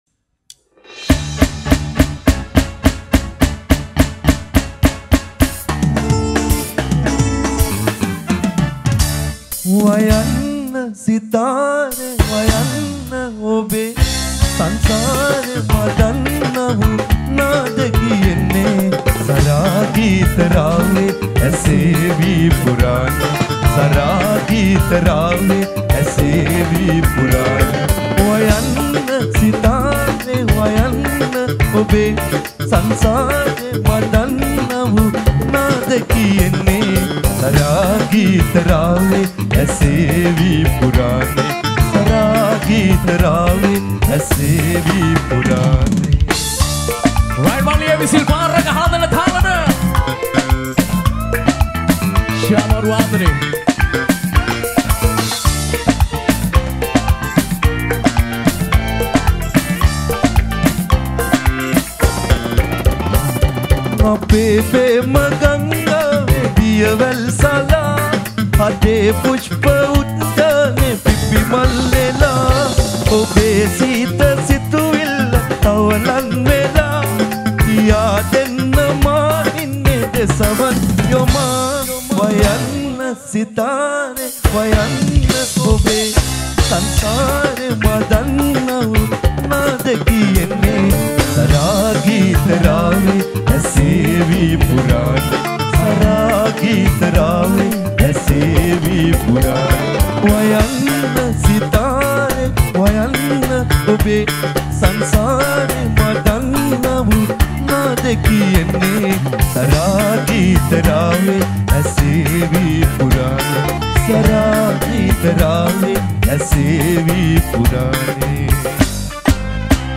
Releted Files Of Sinhala Live Show Nonstop